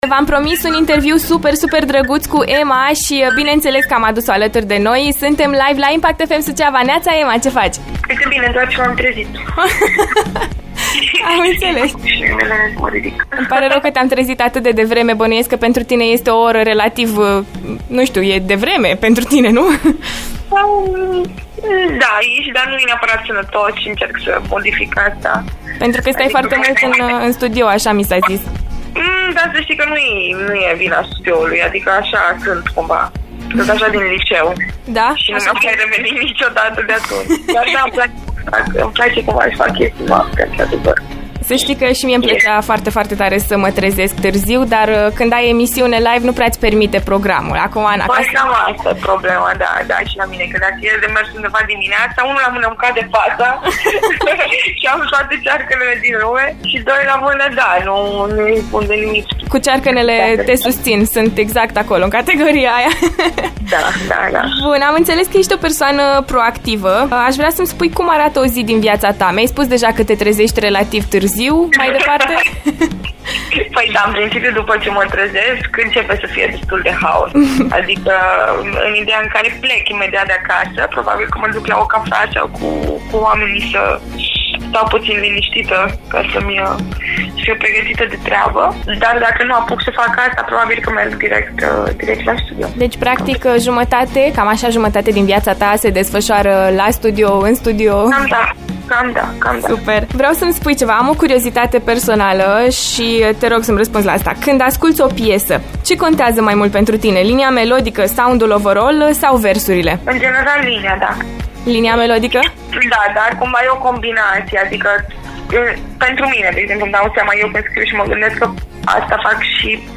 CONVERSAȚII COSMICE CU EMAA, LIVE LA AFTER MORNING
Mai multe detalii despre ceea ce urmează în 2021 îți spune chiar artista: